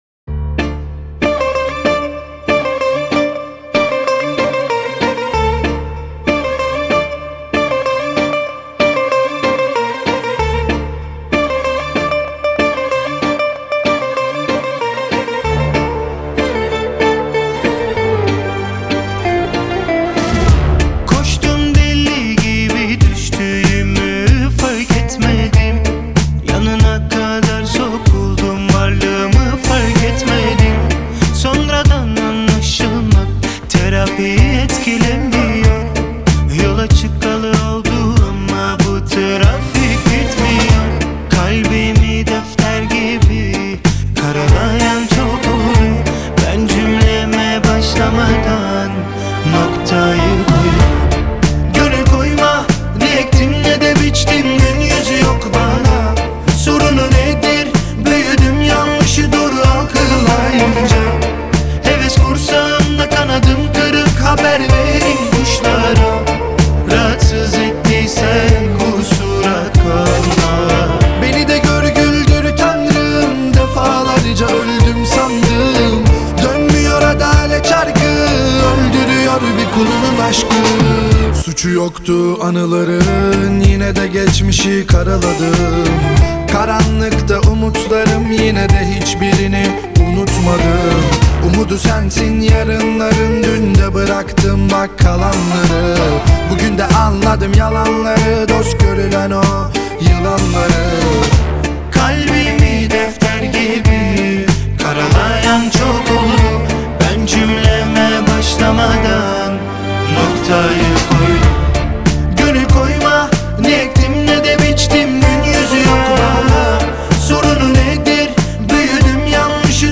это трек в жанре турецкой поп-музыки